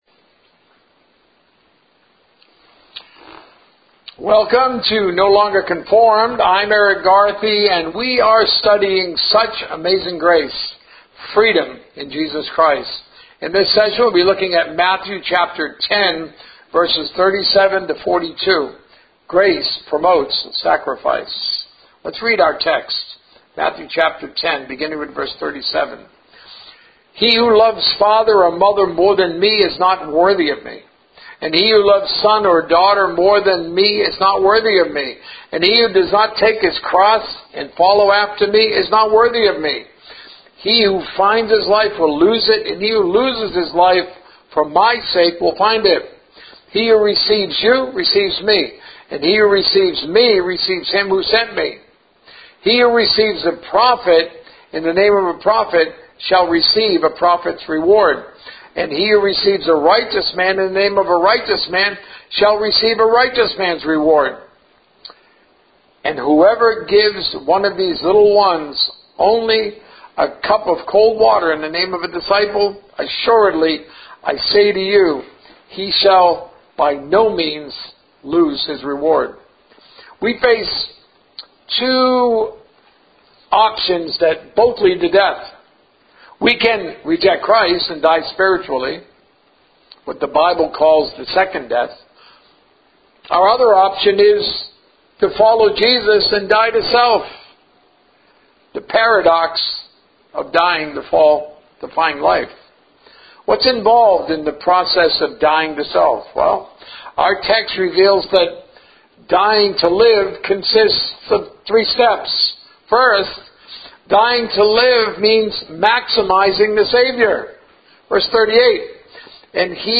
A message from the series "Such Amazing Grace 2025."